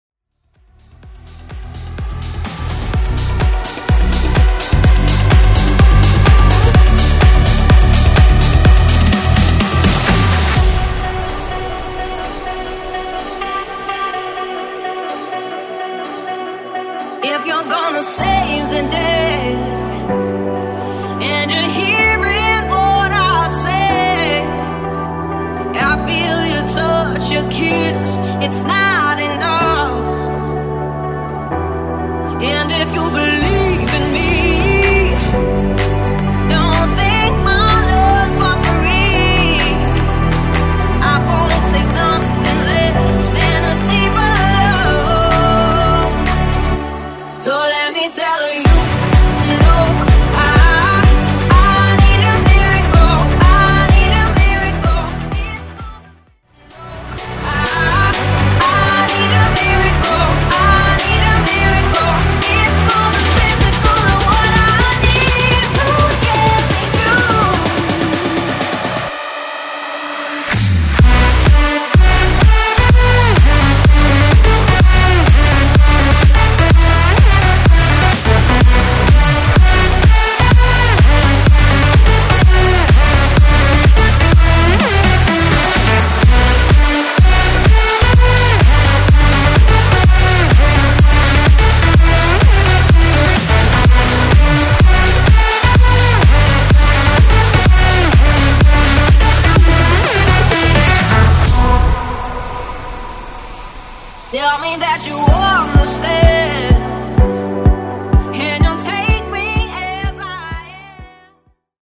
Genre: HIPHOP
Clean BPM: 98 Time